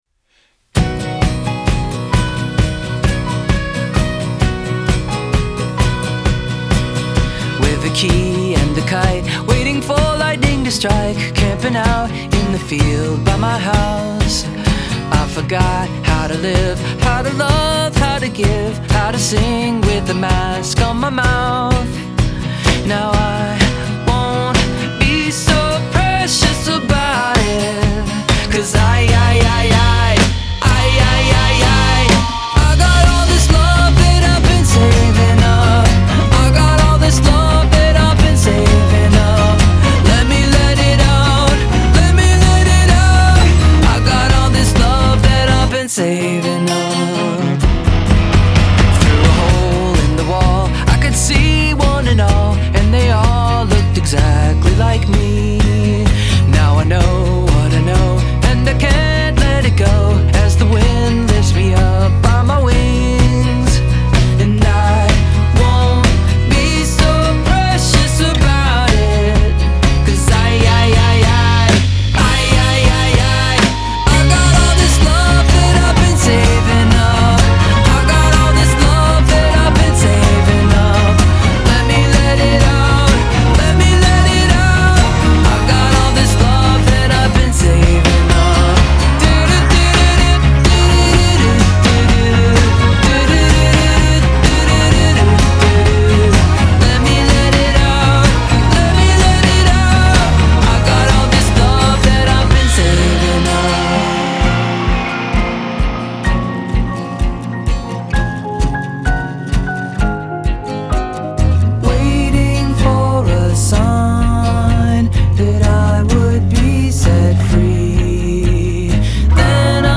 Genre : Alternative, Indie